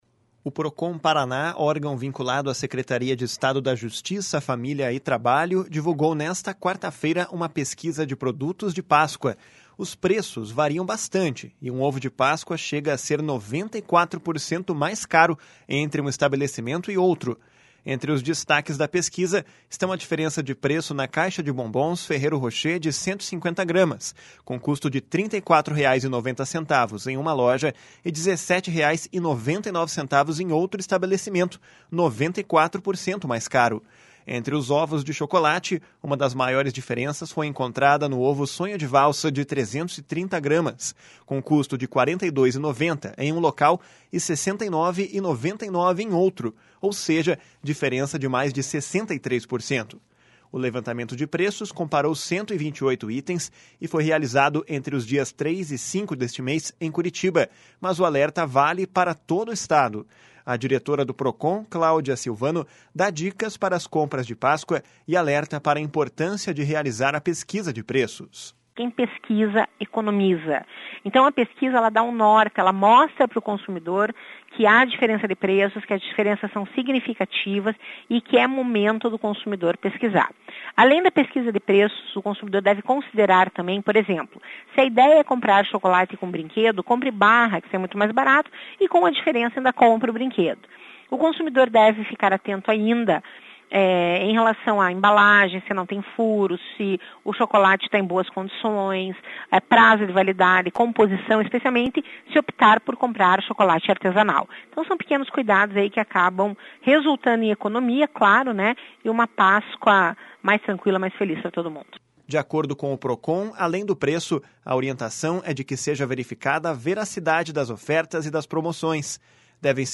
A diretora do Procon, Claudia Silvano, dá dicas para as compras de Páscoa e alerta para importância de realizar a pesquisa de preços.